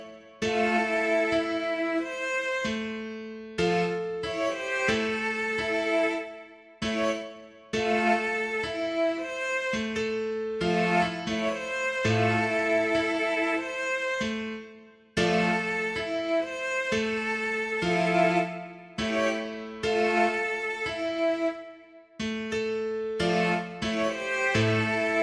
Продукт уже можно генерировать, но сейчас его качество сложно назвать приемлемым, сеть показывает лишь базовое понимание гармонических сочетаний и ритма.